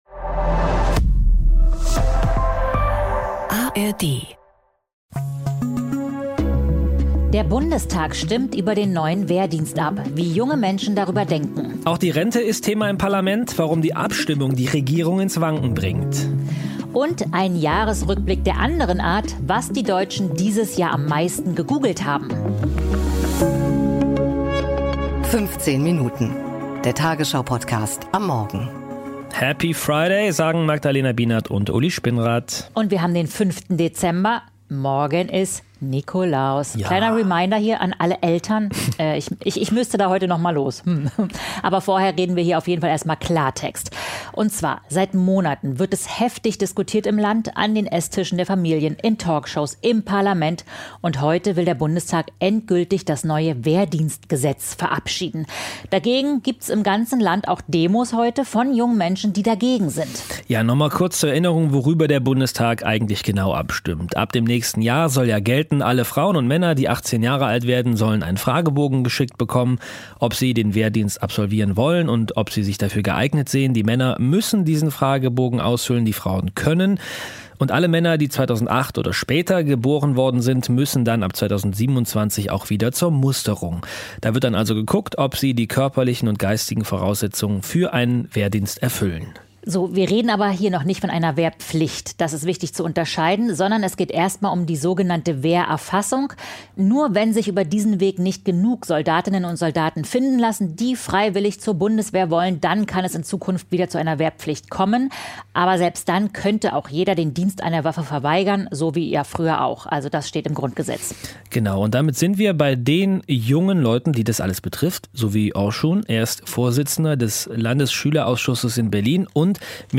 Bei uns hört ihr, wie unterschiedlich junge Menschen auf die Pläne der Bundesregierung schauen.